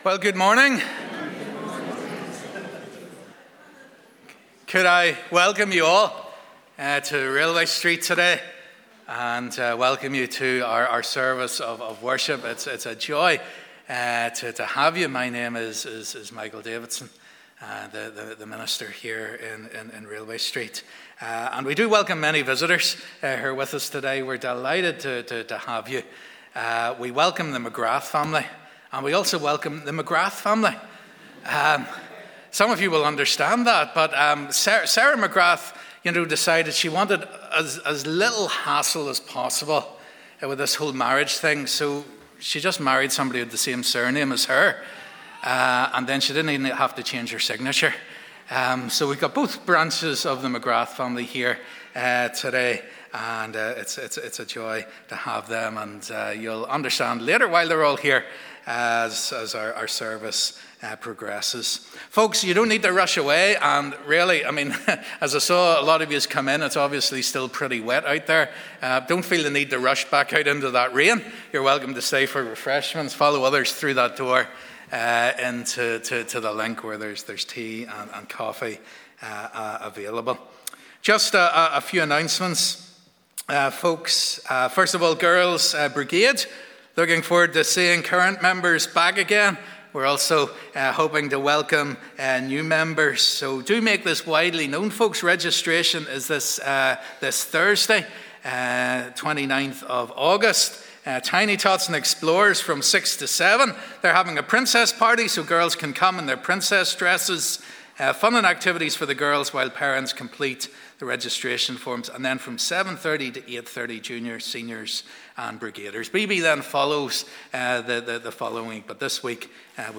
Morning Service